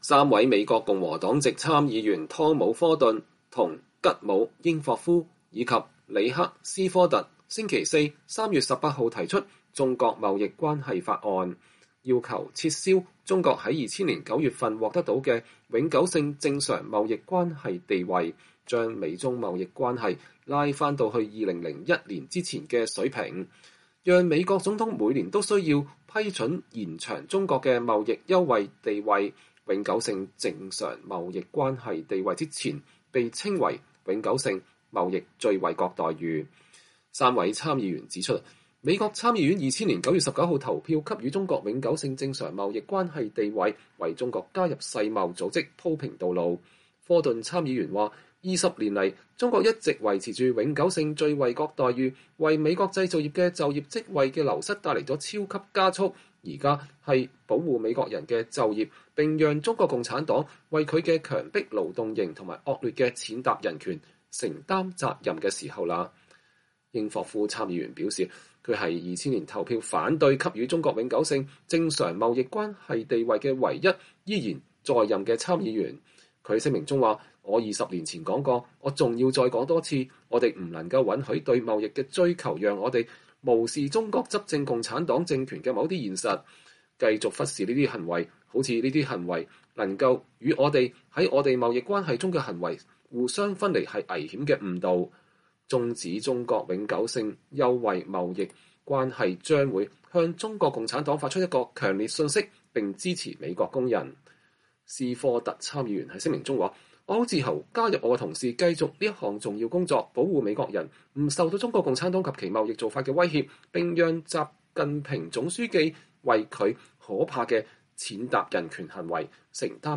美國共和黨籍參議員科頓在參議院發表講話（2020年5月5日）